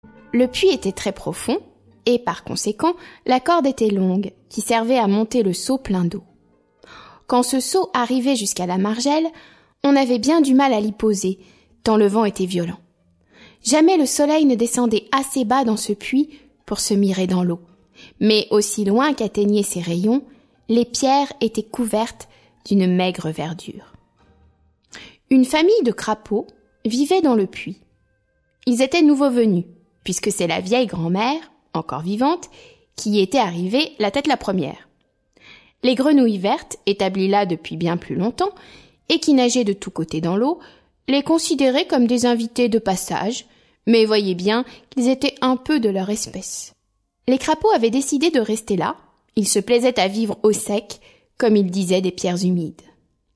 Diffusion distribution ebook et livre audio - Catalogue livres numériques
Musique : Delibes (Sylvia Pizzicato) et Debussy (l'après midi du faune)